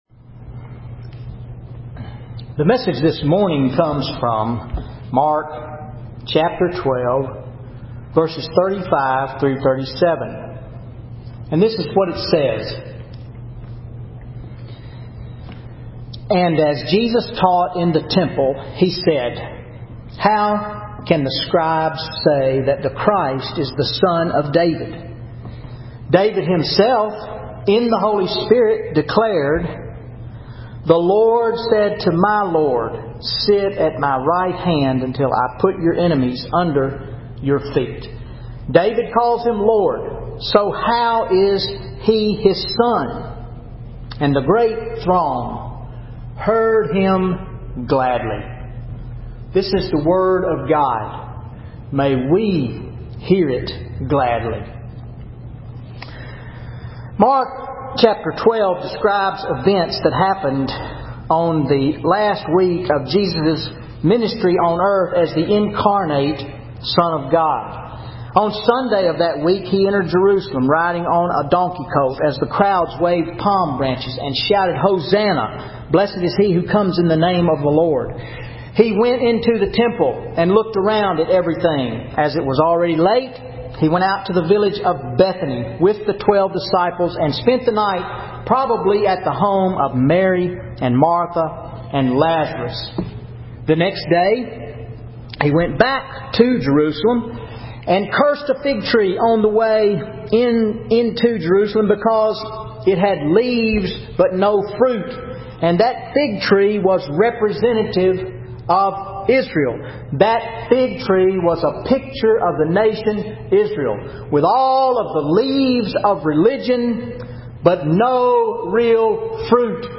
Sermon Mark 12:35-37